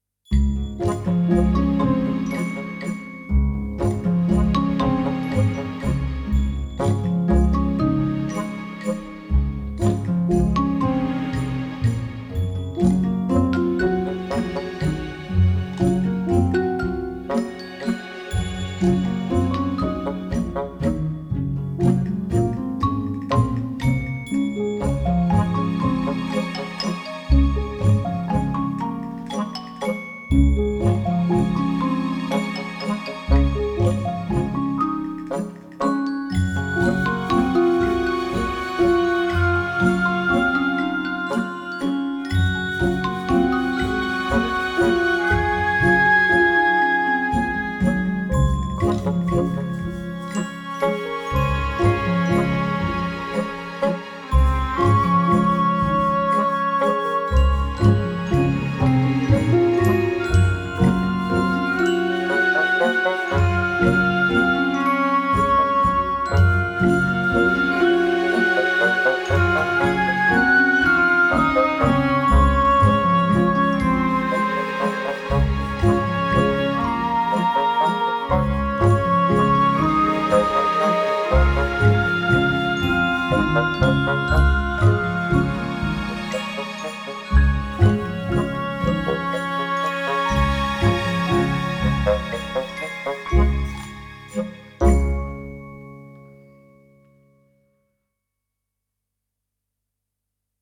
PITCHED PERCUSSION ONLY